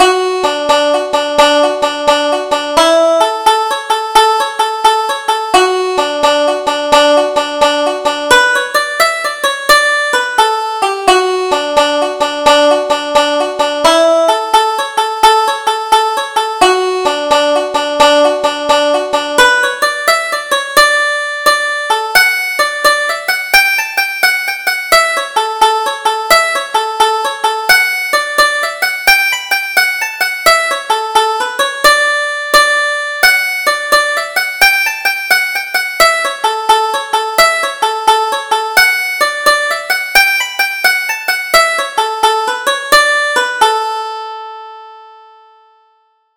Double Jig: The Beauties of Ireland